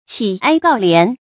乞哀告憐 注音： ㄑㄧˇ ㄞ ㄍㄠˋ ㄌㄧㄢˊ 讀音讀法： 意思解釋： 哀：憐憫；告：請求。